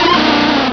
Cri d'Insolourdo dans Pokémon Rubis et Saphir.